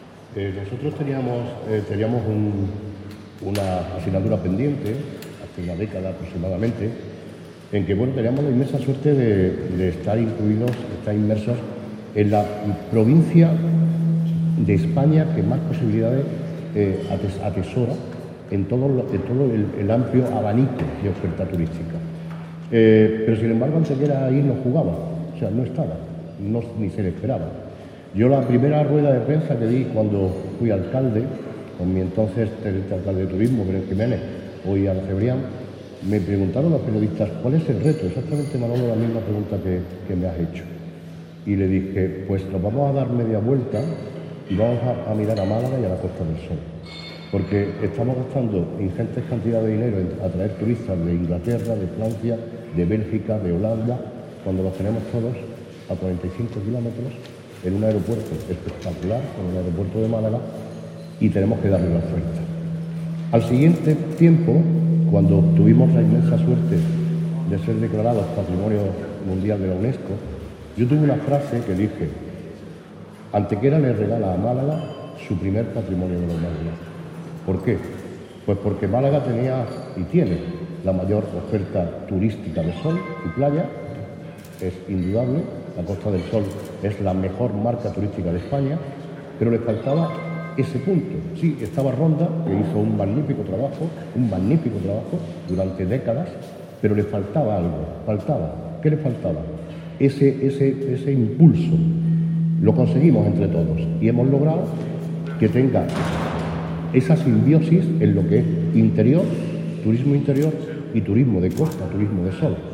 El Hotel Convento de la Magdalena de Antequera ha acogido en la mañana de hoy el desarrollo de la "Jornada de Turismo de Interior. Desafíos y Oportunidades", iniciativa que ha organizado conjuntamente la Asociación de Empresarios Hoteleros de la Costa del Sol (AEHCOS) y el periódico "Sur", contando para ello con el patrocinio de la Diputación de Málaga y Turismo Costa del Sol.
Cortes de voz